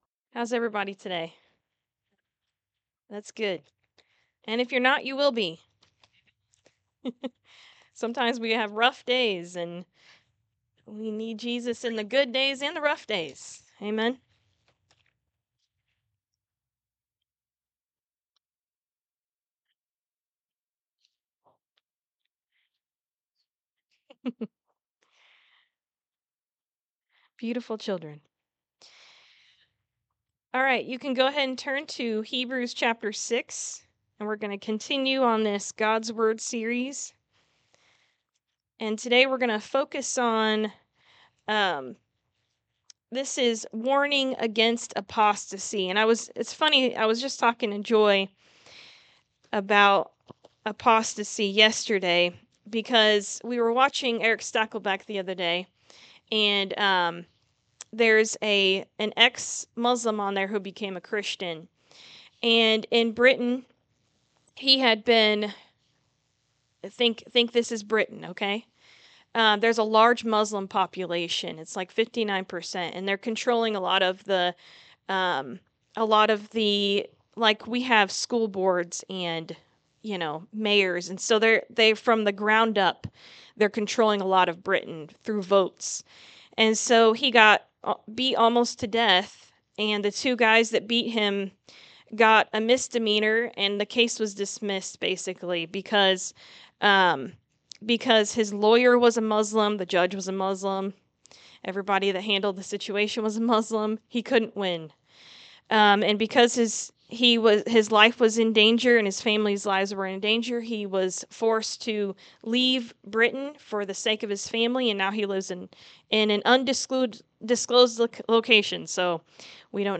God’s Word Series Sermon 7 Warning of Apostasy